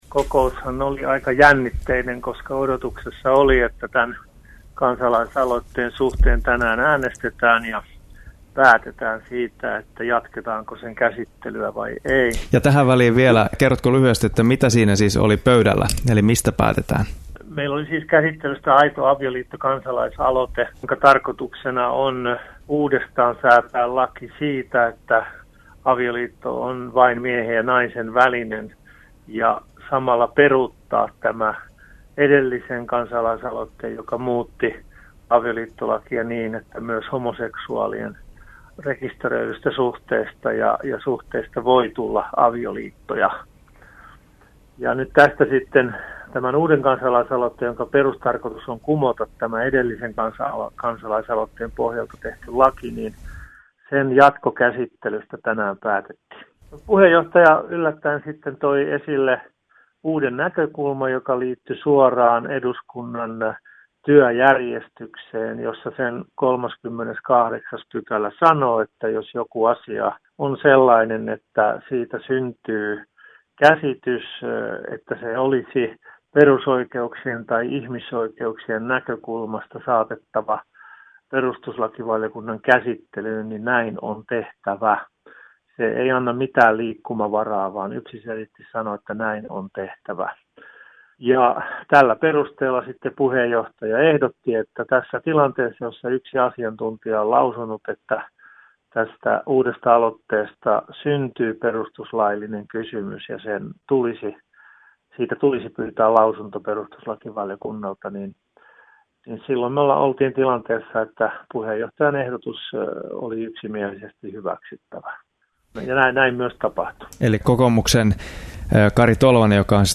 Kristillisdemokraattien kansanedustaja ja lakivaliokunnan jäsen Antero Laukkanen kertoi Parempi huomen -lähetyksessä keskiviikkona 16.11. tuoreeltaan heti kokouksen jälkeen kansalaisaloitteen kohtalosta. Lausunto tulee arvion mukaan lakivaliokuntaan joulukuussa.